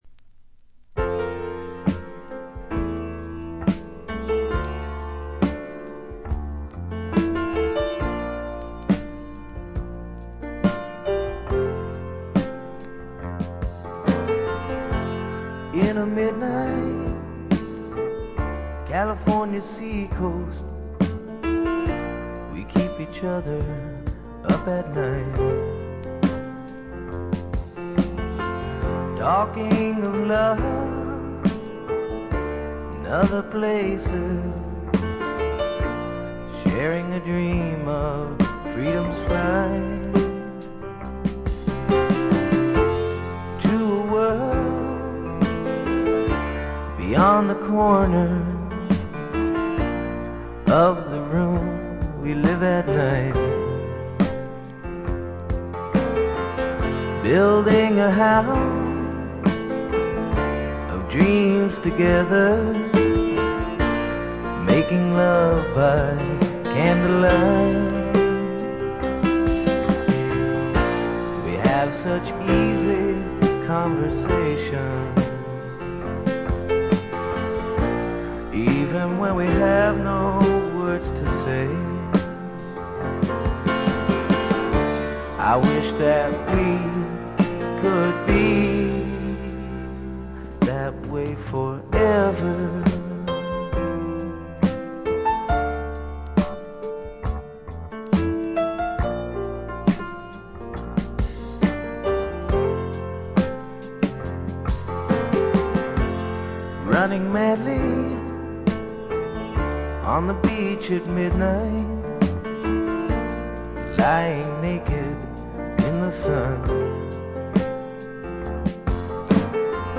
わずかなノイズ感のみ。
渋みのあるヴォーカルも味わい深い88点作品。
試聴曲は現品からの取り込み音源です。